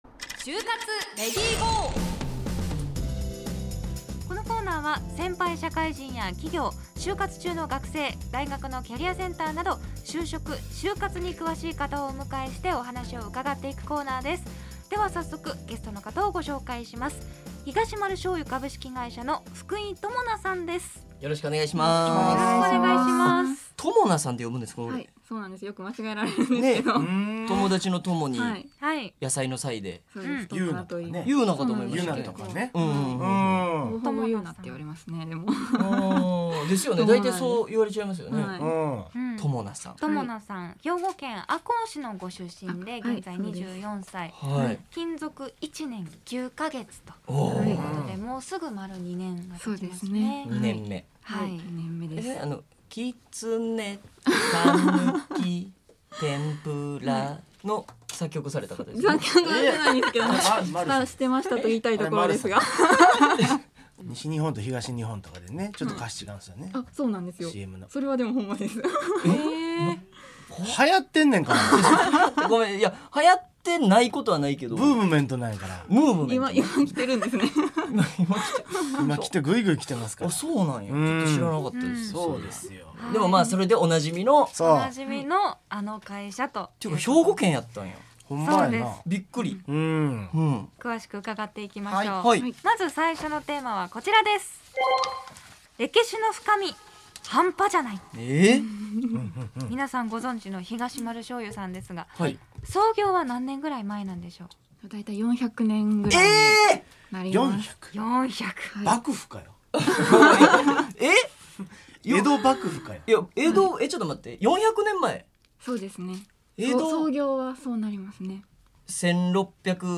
『ネイビーズアフロのレディGO！HYOGO』2021年01月22日放送回（「就活レディGO！」音声）